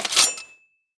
knife_deploy1.wav